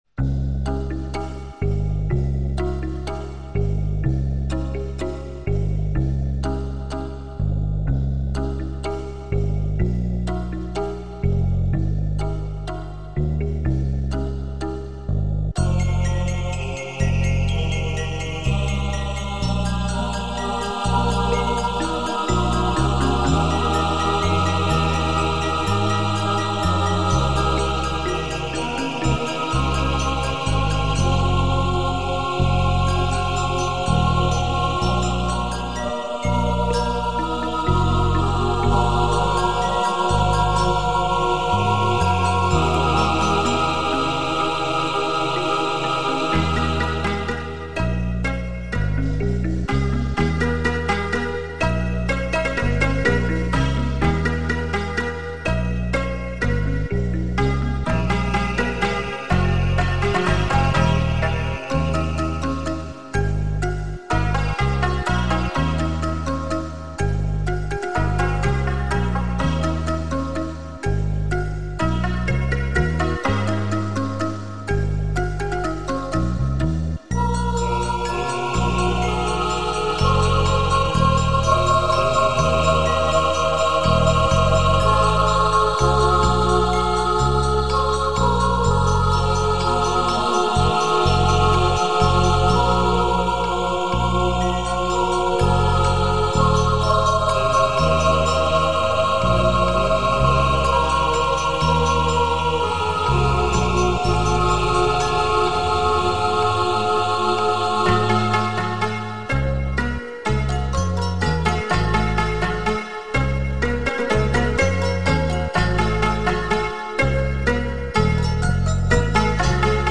【所属类别】音乐 国乐/民族
发烧音效，完美天成。